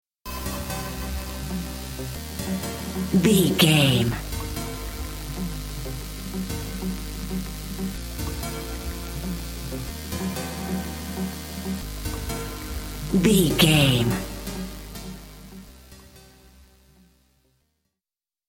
Ionian/Major
E♭
dreamy
ethereal
happy
hopeful
synthesiser
drum machine
house
electronic
synth leads
synth bass
upbeat